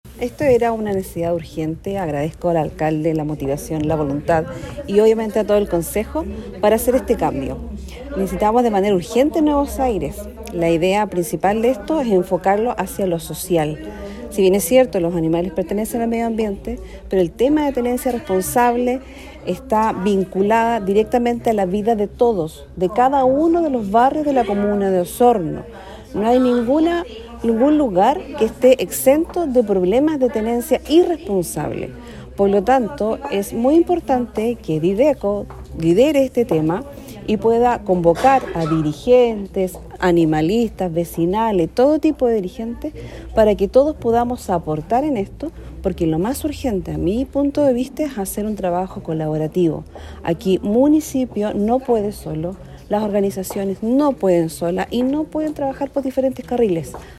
Por su parte la Concejala Cecilia Canales, indicó que esta era una necesidad urgente para dar un enfoque social a la tenencia responsable, pues es una situación que se da en todos los barrios de Osorno y así trabajar en conjunto con las organizaciones.